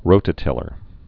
(rōtə-tĭlər)